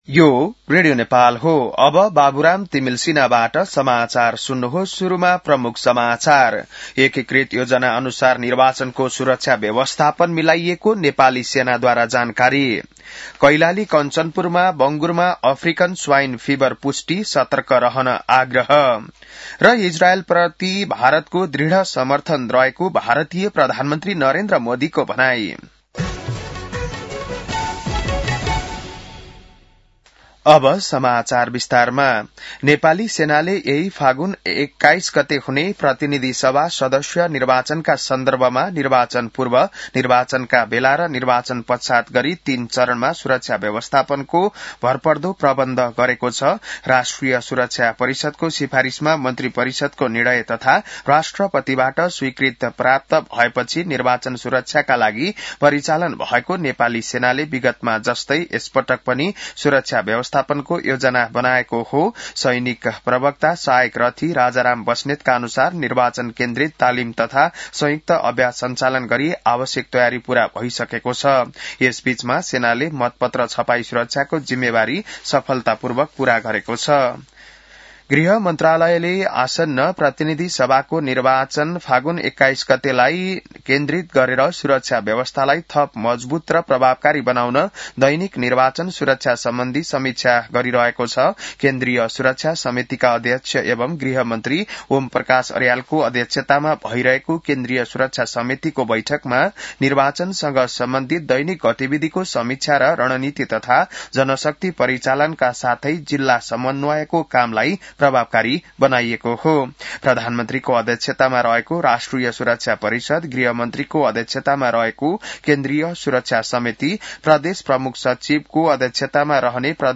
An online outlet of Nepal's national radio broadcaster
बिहान ९ बजेको नेपाली समाचार : १४ फागुन , २०८२